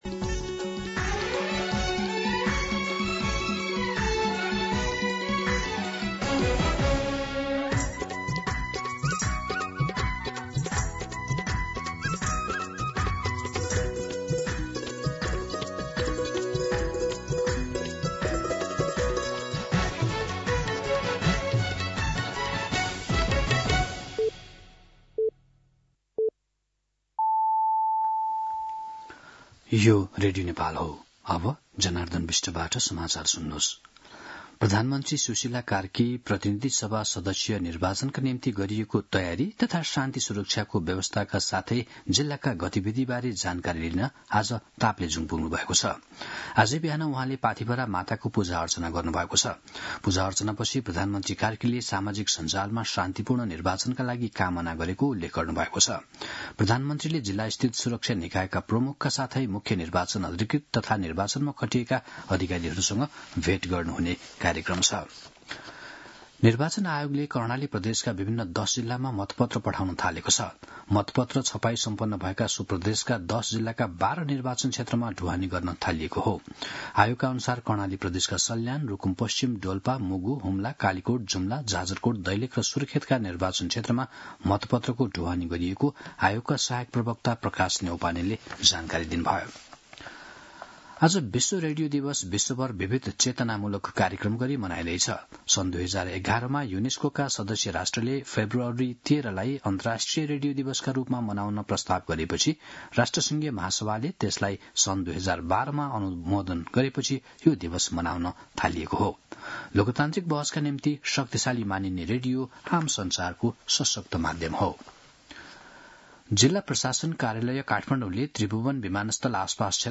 मध्यान्ह १२ बजेको नेपाली समाचार : १ फागुन , २०८२